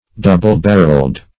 Double-barreled \Dou"ble-bar`reled\, or -barrelled \-bar`relled\